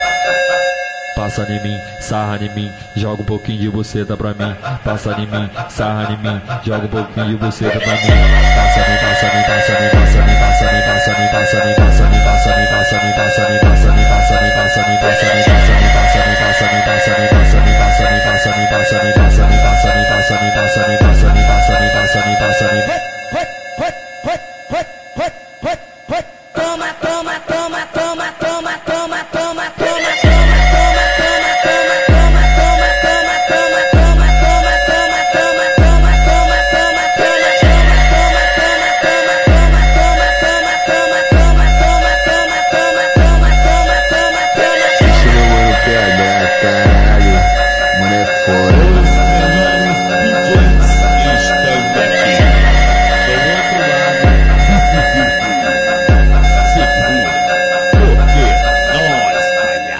bass boosted remix songs